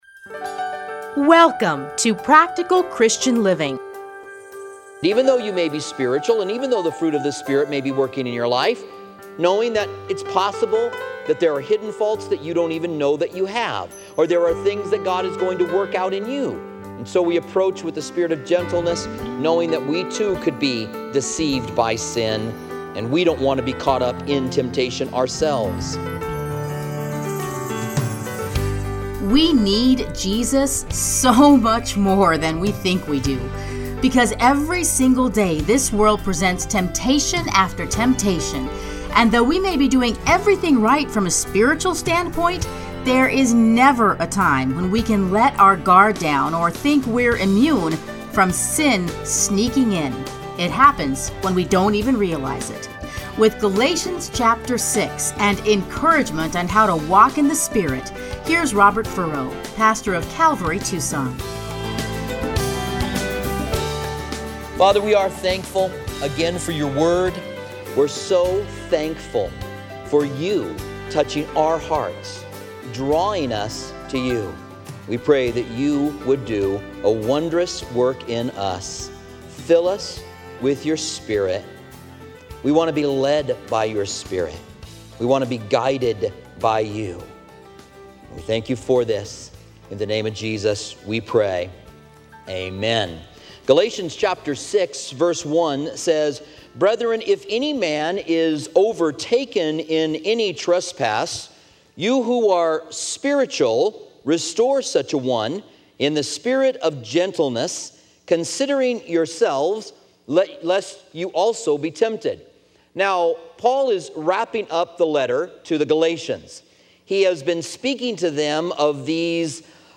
Listen here to his commentary on Galatians.